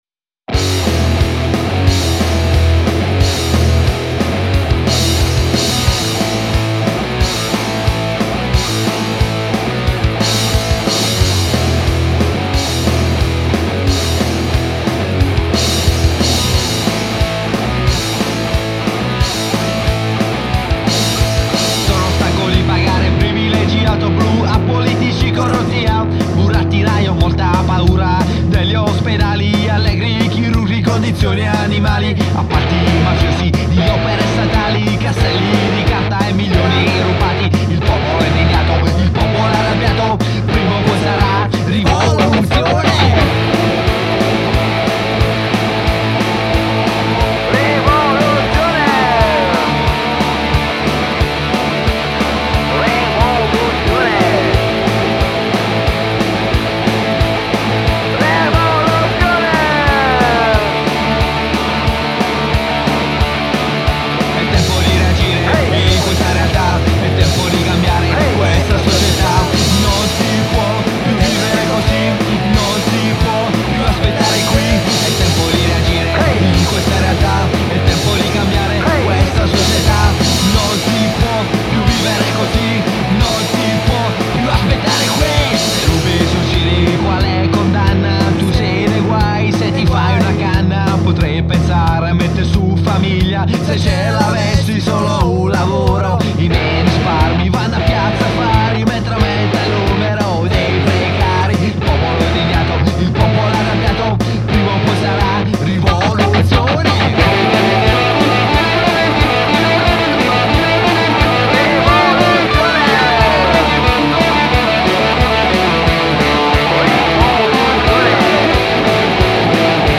punk-rock band
Una punk rock band